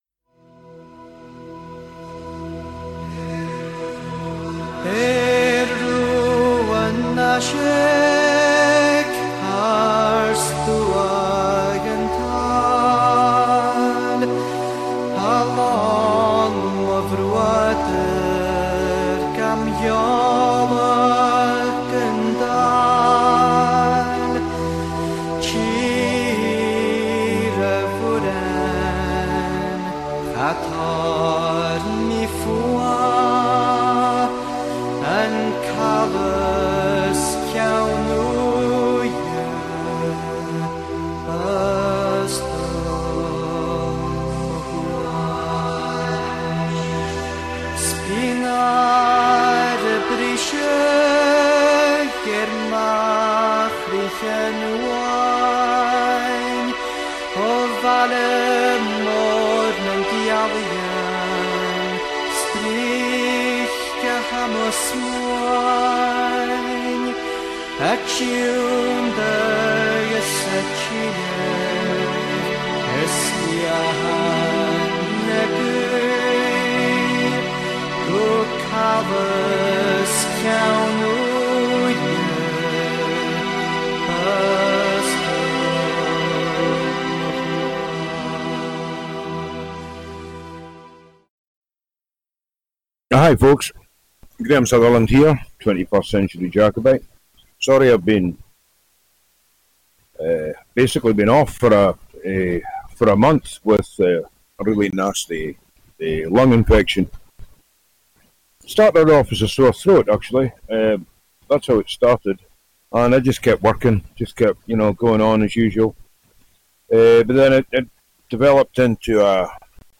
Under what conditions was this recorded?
Callers are welcome to contribute. This weekly radio show broadcasts live every Thursday from Inverness, Scotland, transmitting real, uncensored and unsanitized philosophy, news and perspectives.